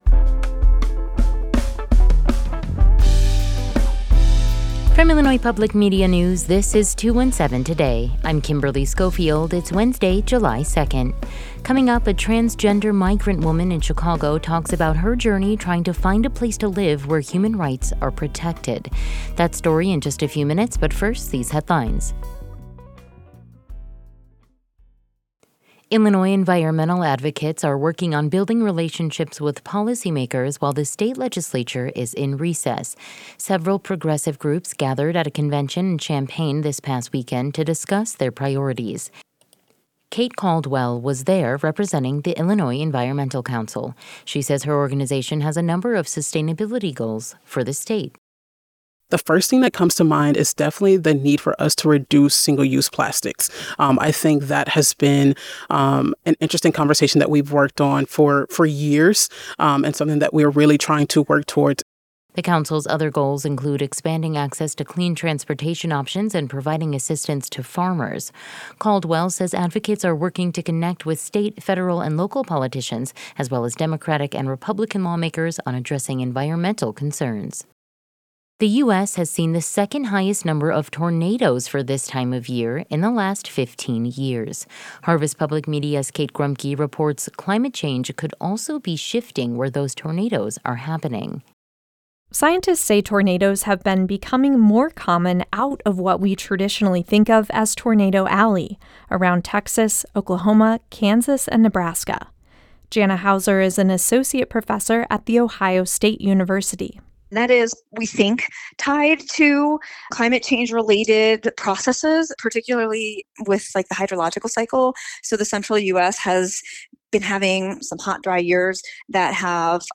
In today’s deep dive, a transgender migrant woman in Chicago talks about her journey trying to find a place to live where human rights are protected.